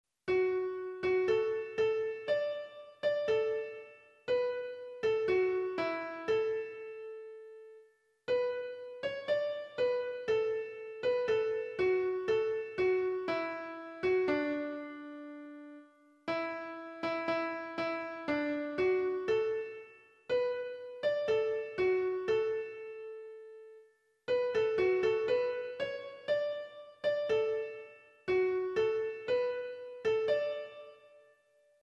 桜峰（おうほう）小学校の校歌